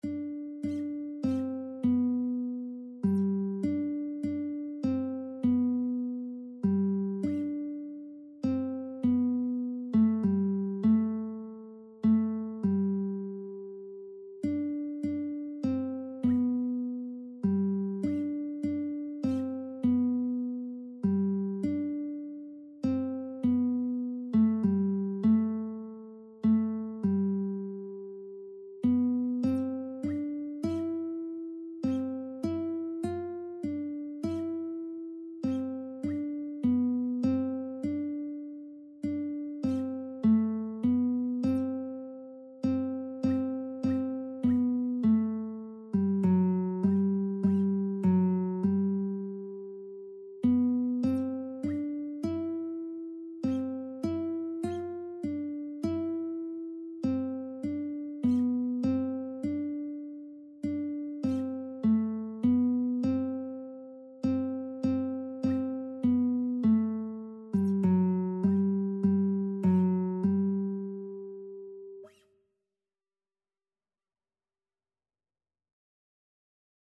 3/4 (View more 3/4 Music)
Classical (View more Classical Lead Sheets Music)